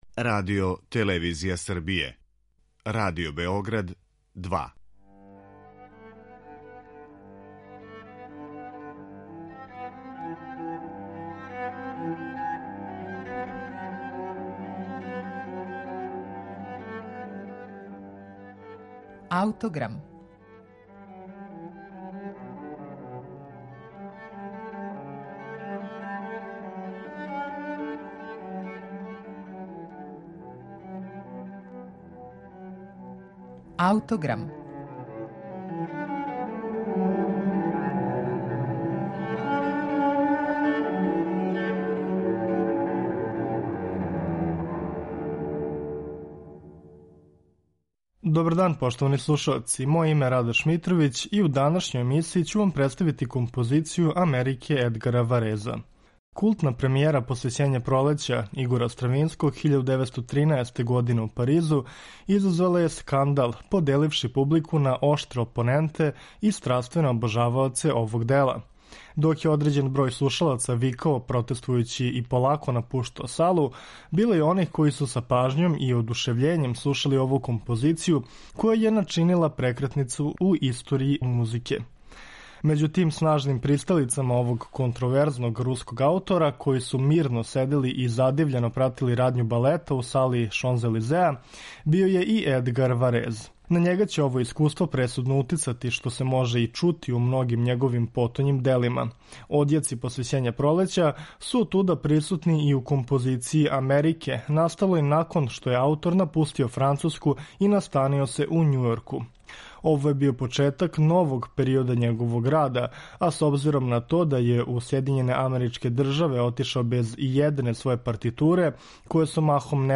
звук сирене